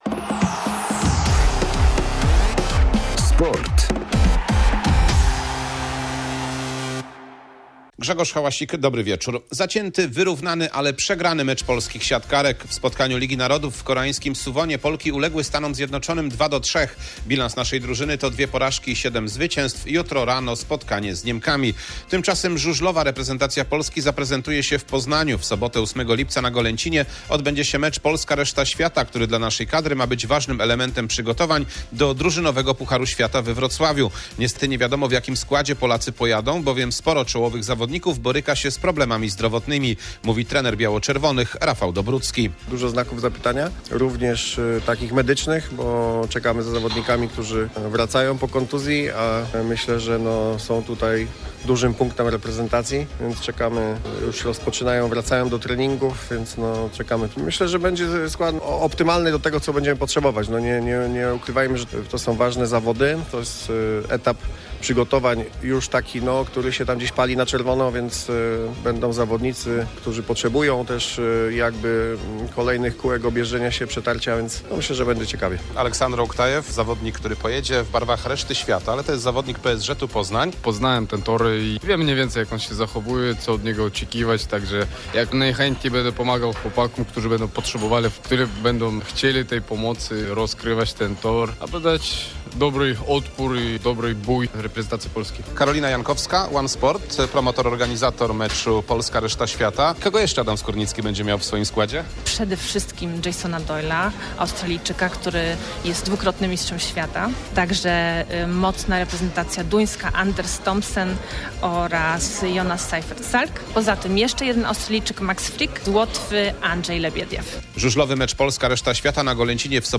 28.06.2023 SERWIS SPORTOWY GODZ. 19:05